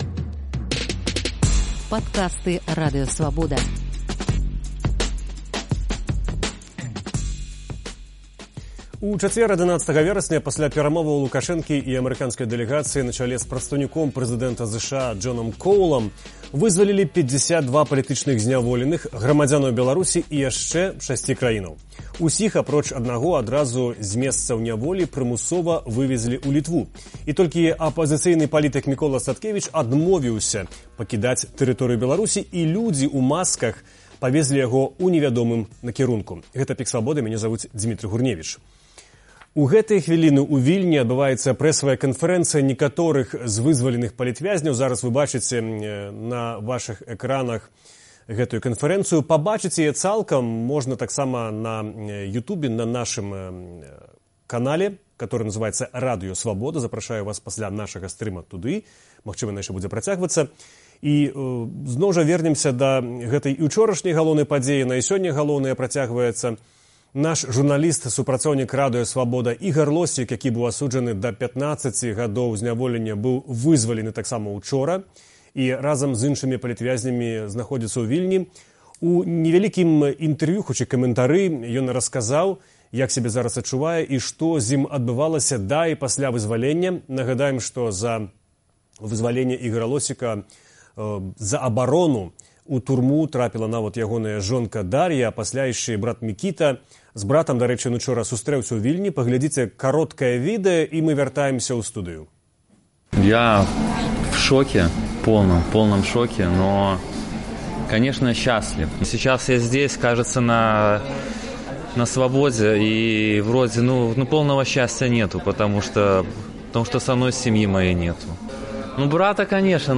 Ён расказаў у эфіры “ПіКа Свабоды” дэталі гэтага здарэньня.